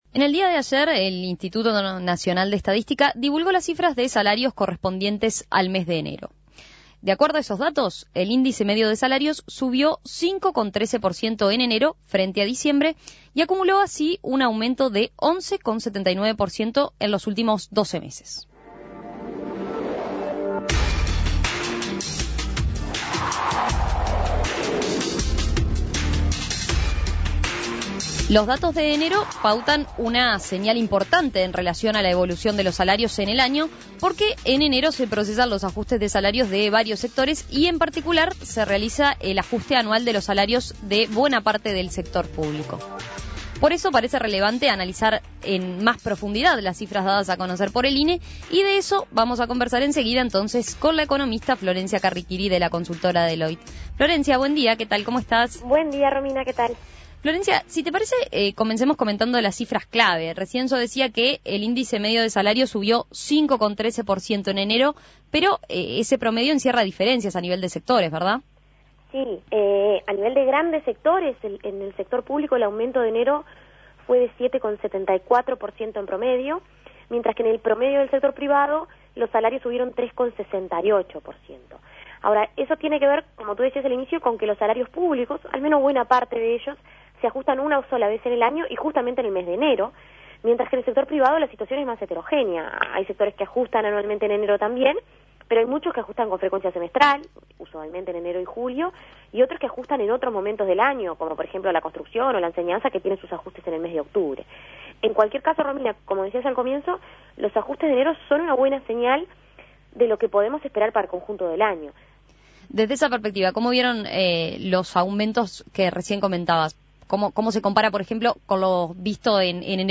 Análisis Económico ¿Cómo se analiza el aumento de los salarios de enero y qué nos dice sobre la suba prevista para este año?